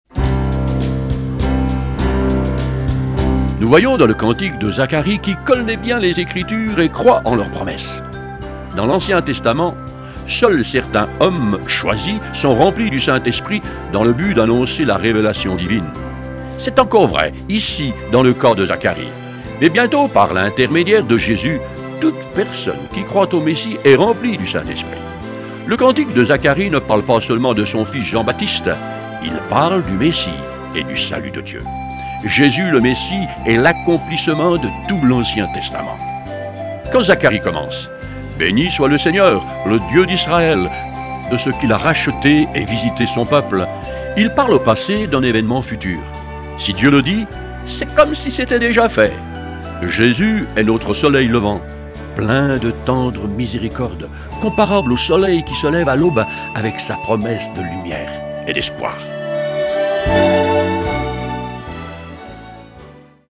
Version audio Phare FM :